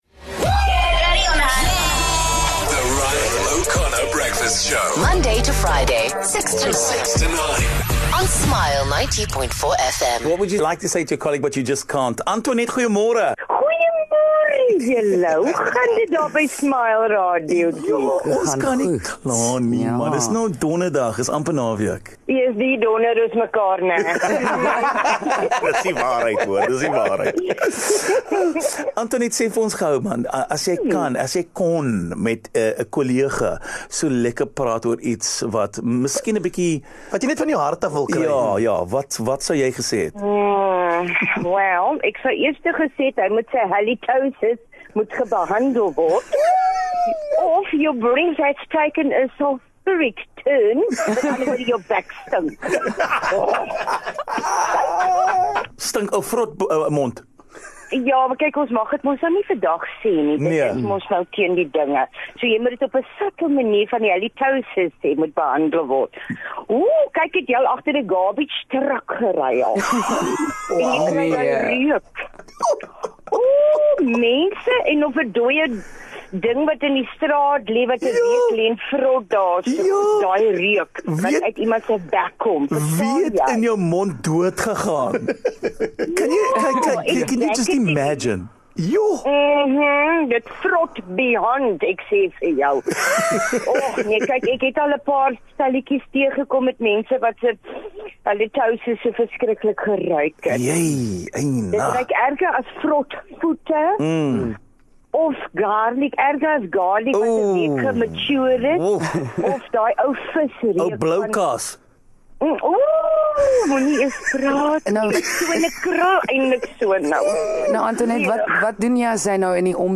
One Smile listener didn't hold back when she called in to tell us what she's had to endure.